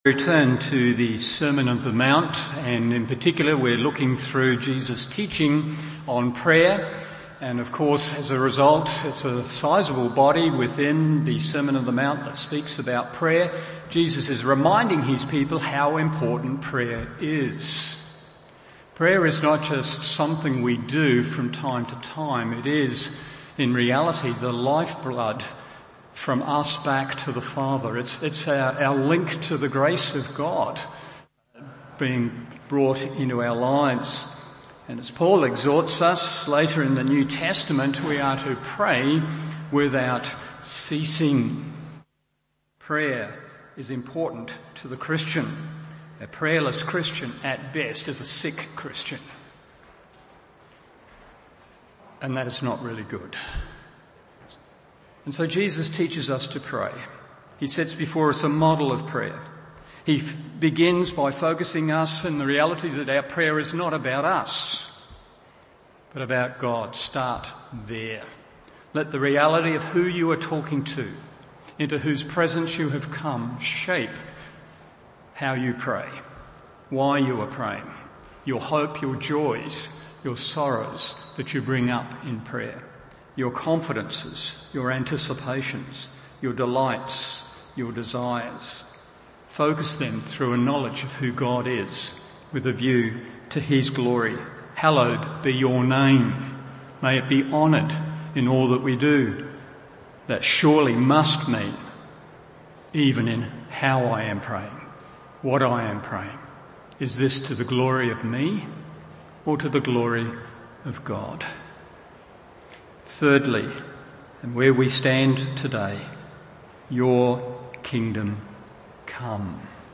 Morning Service Matt 6:10 1. Its Extension in Our Hearts 2. Its Expansion in this World 3. Its Consummation in Final Glory…